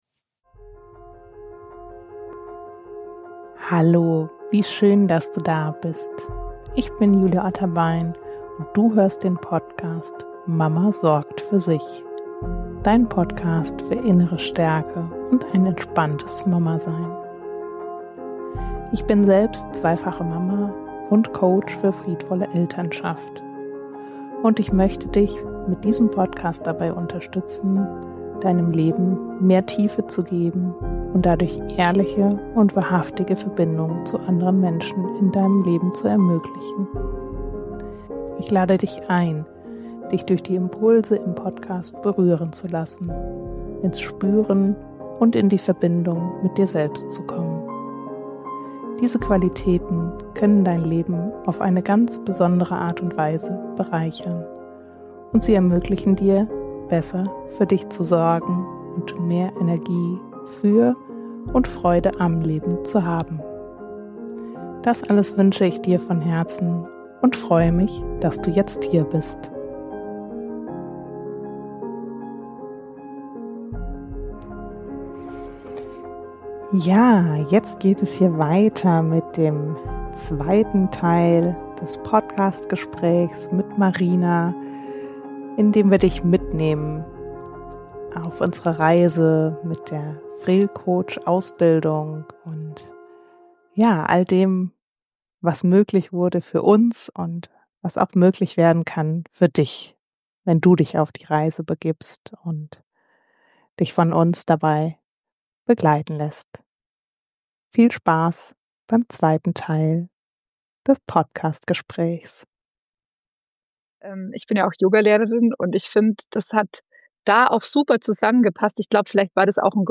Wir sprechen darüber, was FREL für uns persönlich bedeutet, wie sich die friedvolle Haltung auch im Alltag zeigt und warum sie weit über das Elternsein hinauswirkt. Eine ehrliche, lebendige Folge über innere Entwicklung, Mut und Vertrauen in den eigenen Weg.